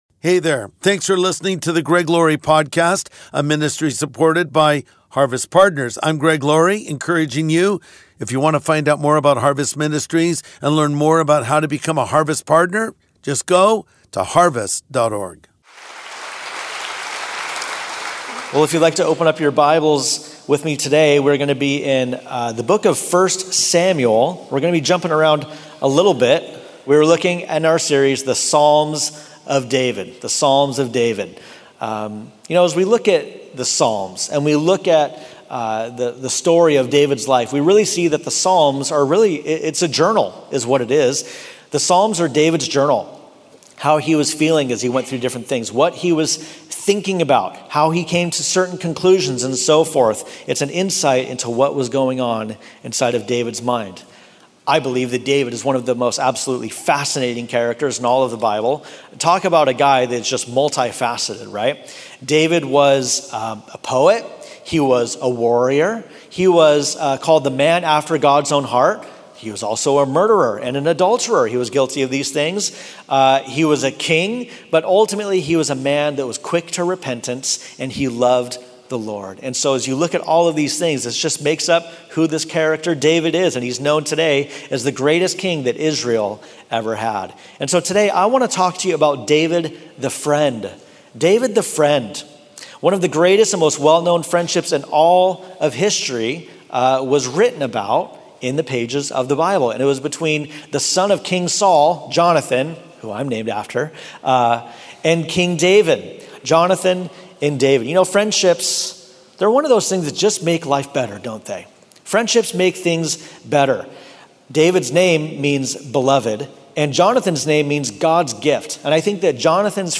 True Friendship | Sunday Message